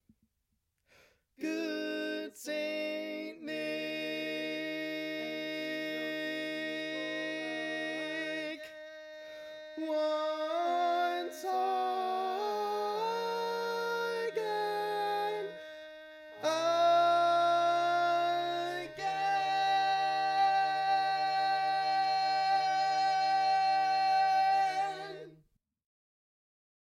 Key written in: E♭ Major
Type: Barbershop